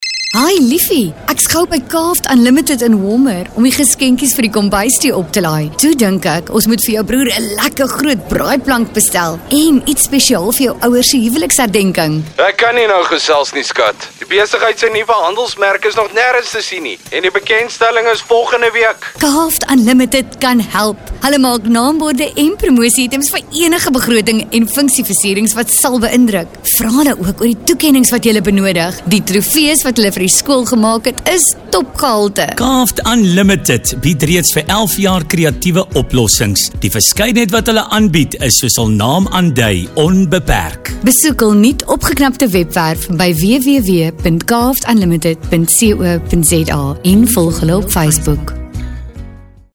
authentic, authoritative, energetic
Afrikaans Dialogue Medium Sell
Afrikaans-Dialogue-medium-sell-1.mp3